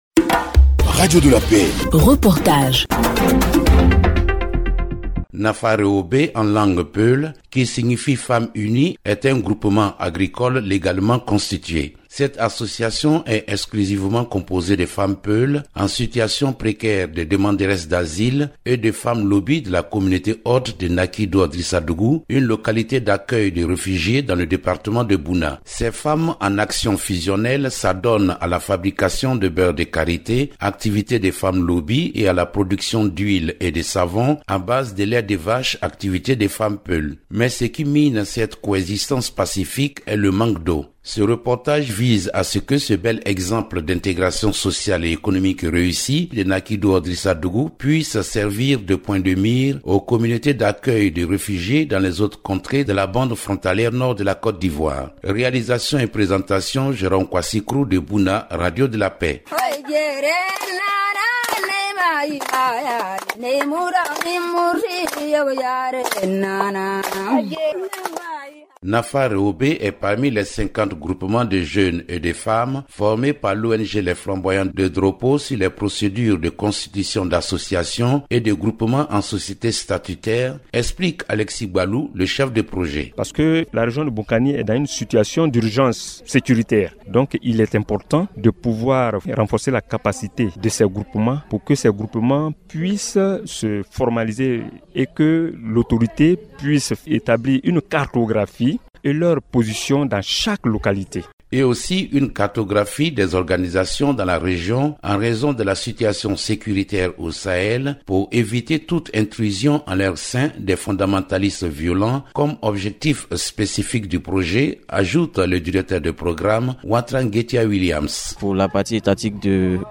Reportage – Nafa Reboué, un groupement agricole modèle d’intégration de femmes réfugiées à Bouna - Site Officiel de Radio de la Paix
reportage-nafa-reboue-un-groupement-agricole-modele-dintegration-de-femmes-refugiees-a-bouna.mp3